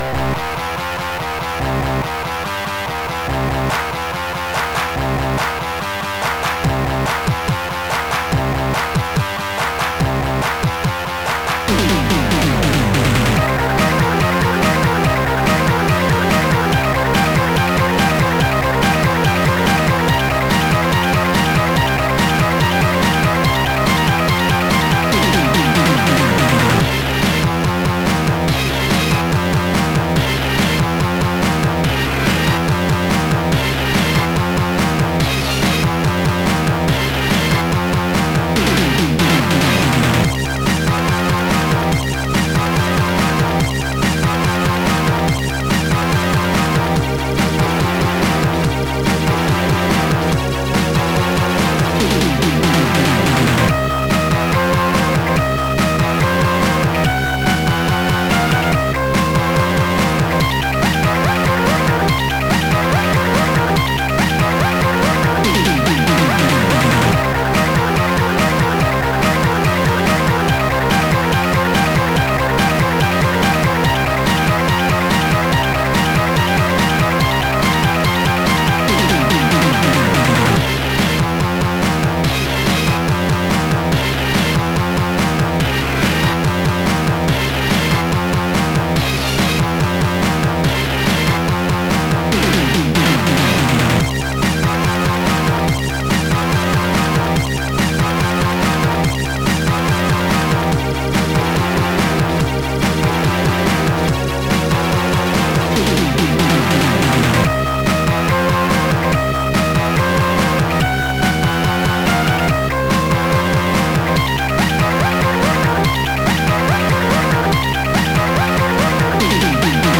Digital gametune 7
This game features digital title music
Music written with Quartet